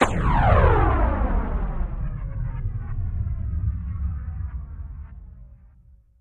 Missle | Sneak On The Lot